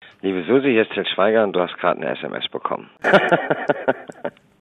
Wenn Du zufällig auf TILL SCHWEIGER stehst- SUSI heißt, oder jemanden kennst der SUSI heißt: Dann ist DAS hier der richtige SMS- Benachrichtigungston für`s Handy!
Ich hab Till Schweiger während eines Telefoninterviews zu seinem neuen Film ONE WAY die ultimative SMS Benachrichtigung für alle SUSI`s rausgekitzelt.